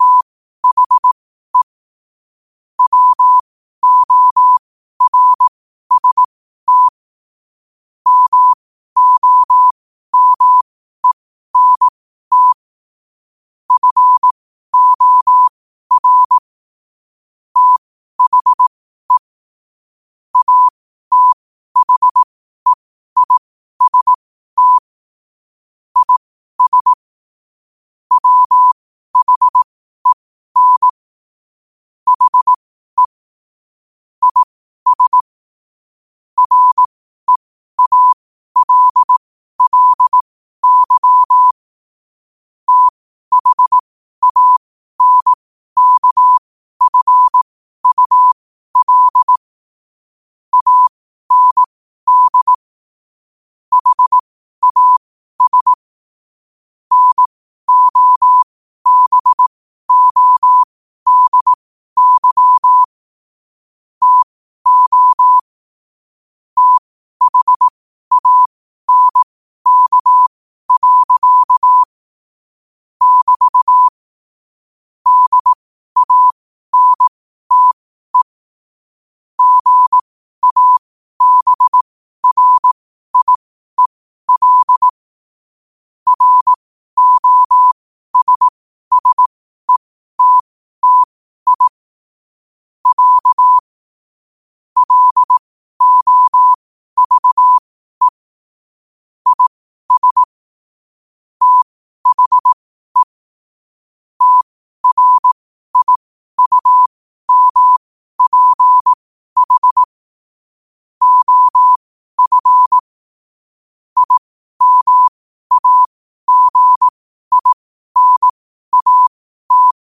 Quotes for Fri, 15 Aug 2025 in Morse Code at 12 words per minute.